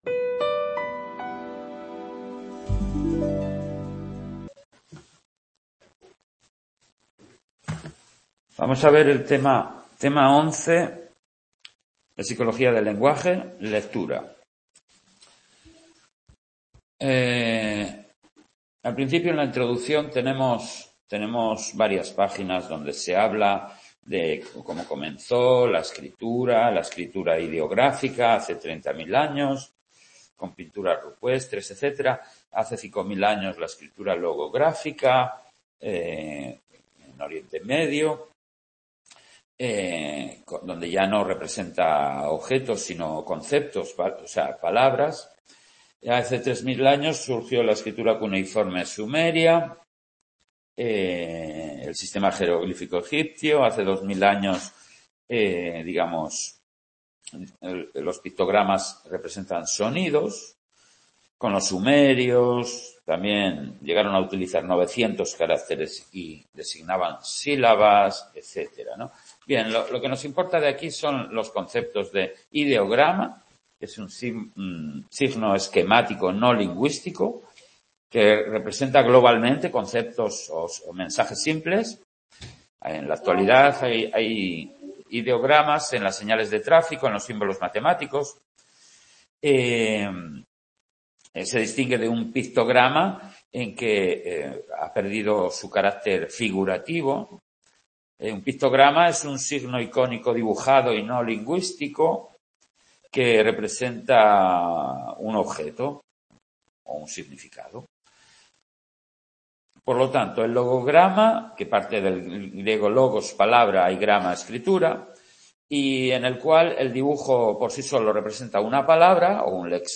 en el Centro Asociado de Sant Boi